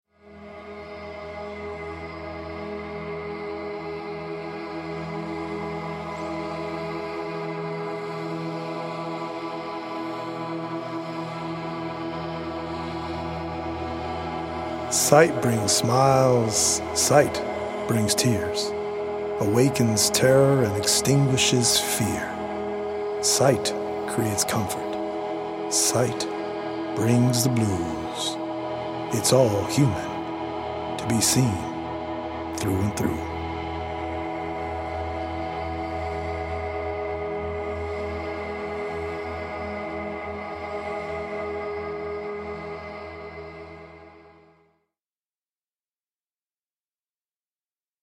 healing Solfeggio frequency music
EDM